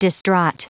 Prononciation du mot distraught en anglais (fichier audio)
Prononciation du mot : distraught